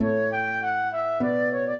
flute-harp
minuet13-7.wav